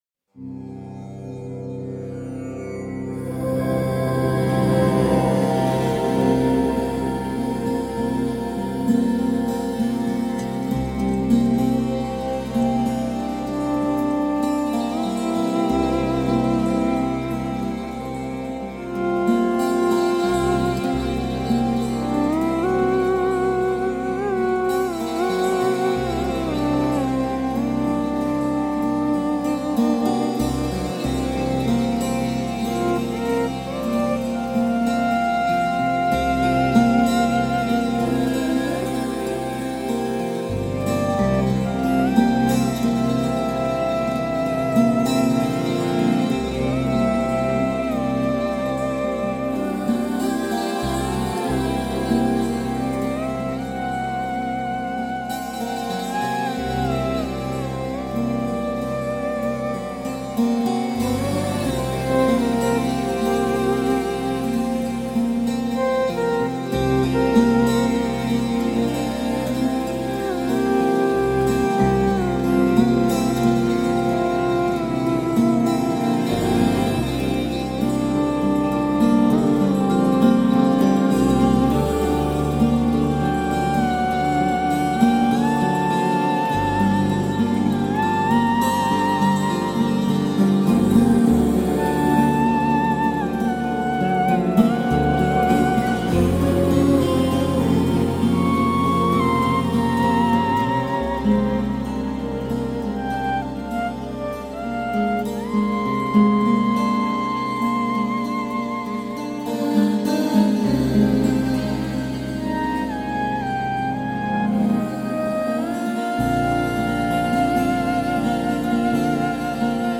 Beautiful ambient space music.
Tagged as: New Age, Ambient